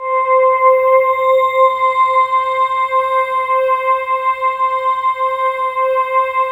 Index of /90_sSampleCDs/USB Soundscan vol.13 - Ethereal Atmosphere [AKAI] 1CD/Partition D/07-ANAPLASS